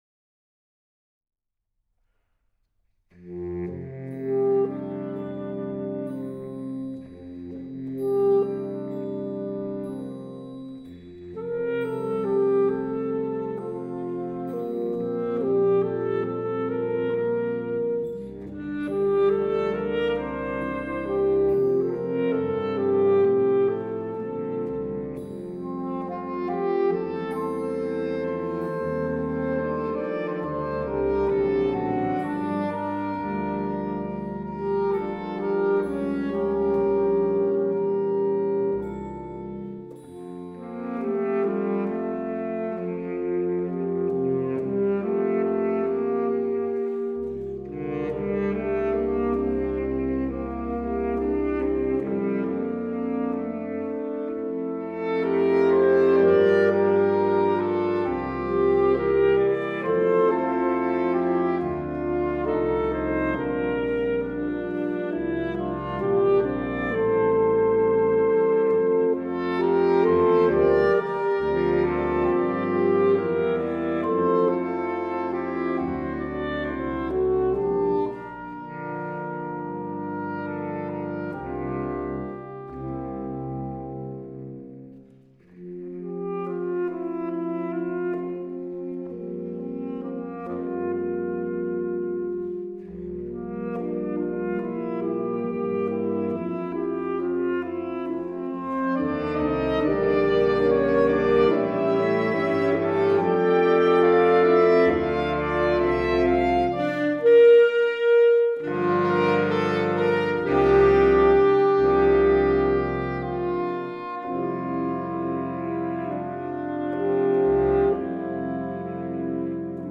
Bladmuziek voor flexibel ensemble.